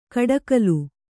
♪ kaḍakalu